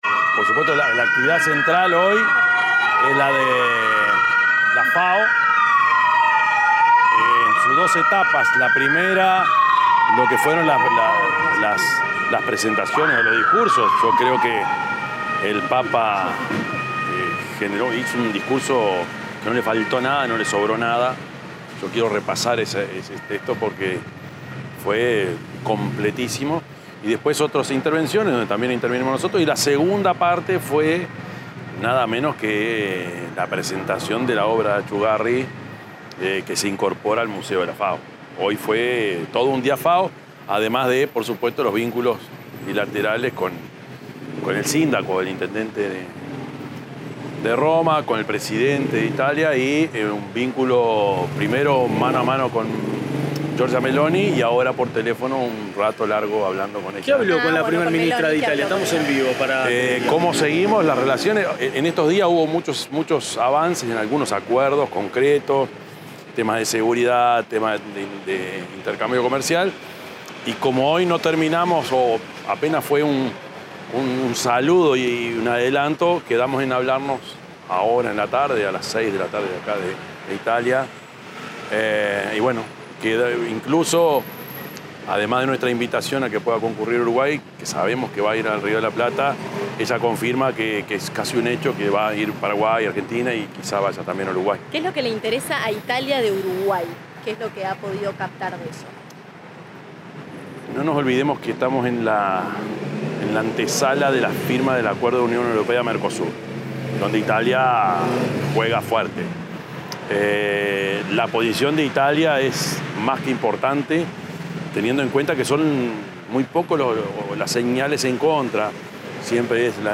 Declaraciones del presidente de la República, Yamandú Orsi, en Roma
En el marco de su gira por Italia, el mandatario informó, en una rueda de prensa, sobre los contactos y reuniones mantenidos con las autoridades